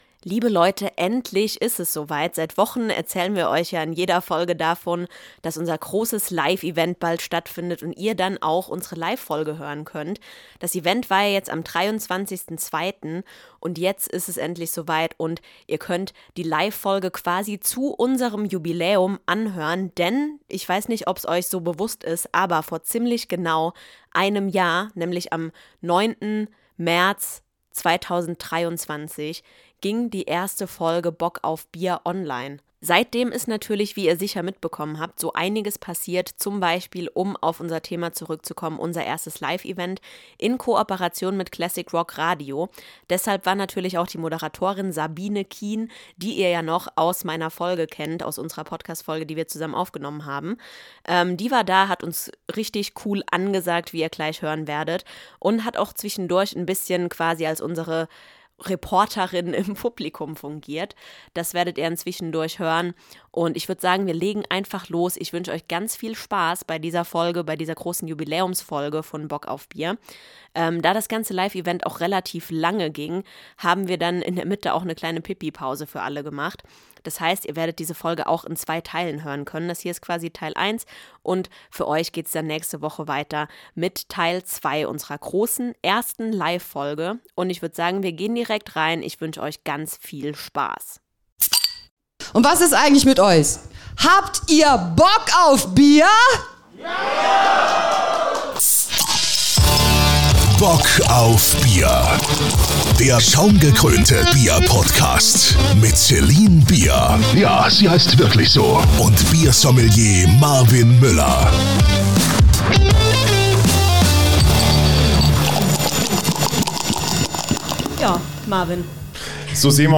Die große Jubiläumsfolge (Live) Teil 1 ~ Bock auf Bier – der schaumgekrönte BIER-PODCAST Podcast
Bock auf Bier wird tatsächlich schon ein Jahr alt! Um das zu feiern fand vor zwei Wochen unser erstes Live-Event statt - eine Podcastaufzeichnung inklusive Biertasting!
Also hört gerne mal rein - es hat uns eine Menge Spaß gemacht, das erste Mal vor Livepublikum zu stehen!